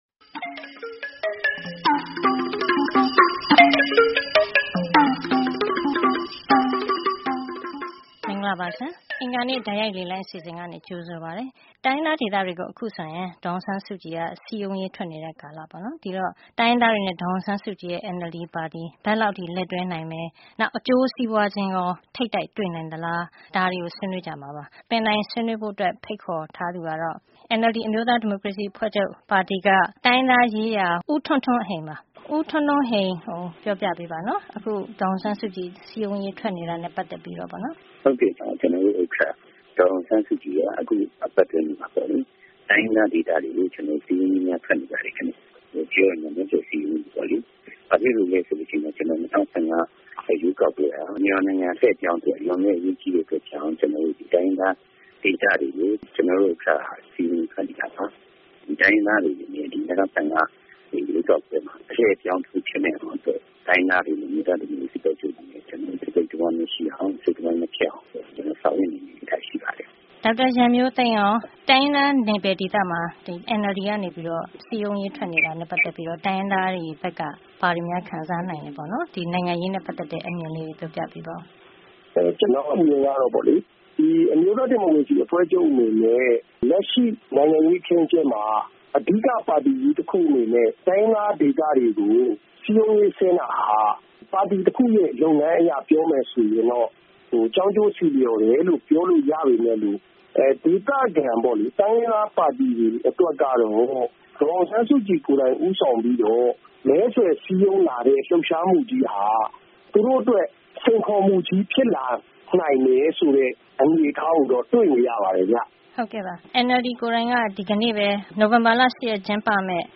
09-15-15 Tueday Call In Show - Does DASSK's Ethnic Area Trip becomes Conflict of Interest between Ethnic Groups and NLD